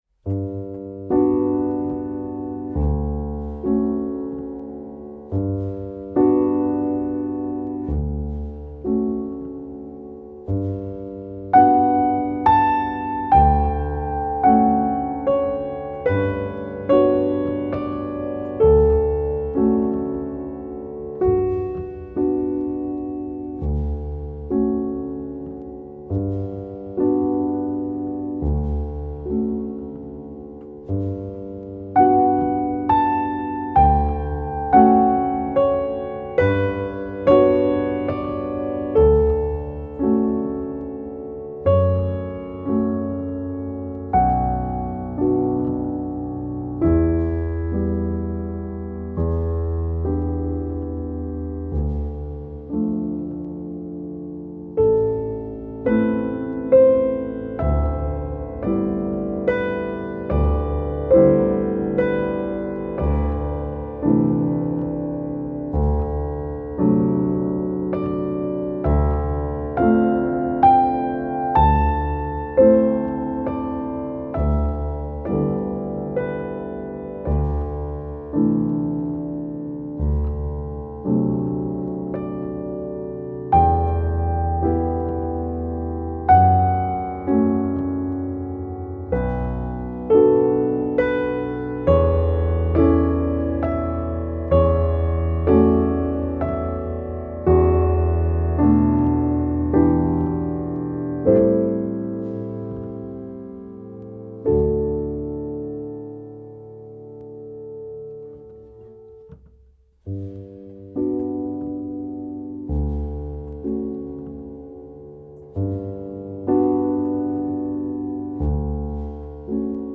Live Recording
piano
recorded in JaneStudio Cagliari, june 2020